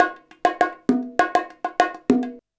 100BONG14.wav